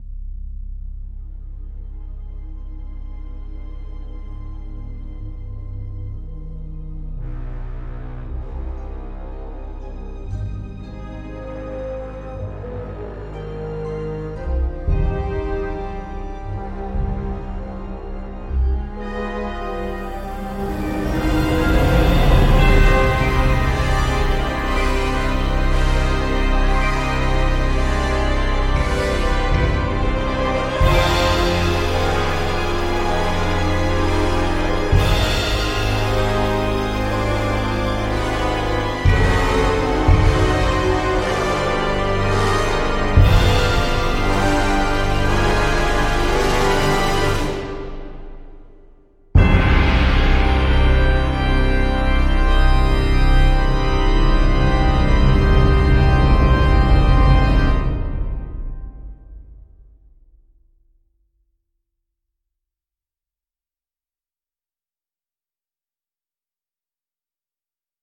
Spitfire Audio Symphonic Organ 是一款专业的管风琴音源，它采样了位于 Rugby School Chapel 的一台巨大的、拥有 3721 根管子的教堂风格的管风琴，创造了一种史诗般的、独一无二的声音。
Spitfire-Audio-Symphonic-Organ.mp3